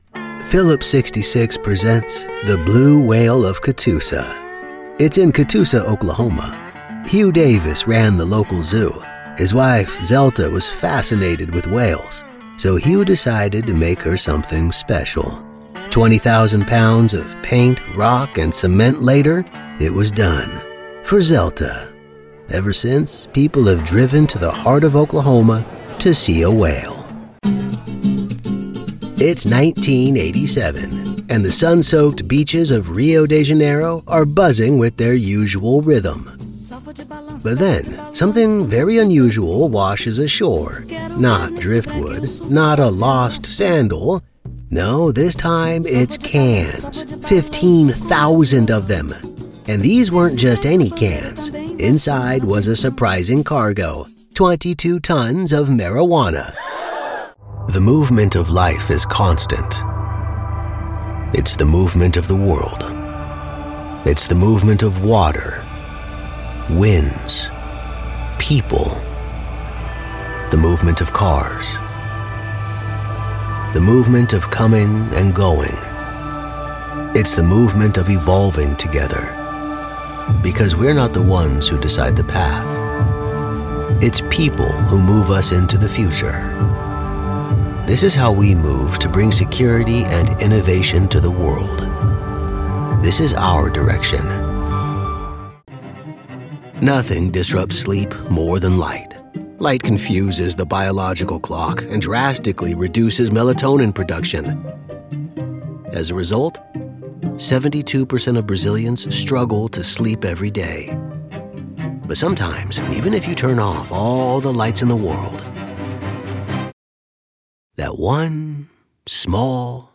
Male
Yng Adult (18-29), Adult (30-50)
Warm, gritty, and believable with a natural storyteller's charm—my voice is perfect for narration, audiobooks, explainer videos, commercials, and eLearning.
Narration Demo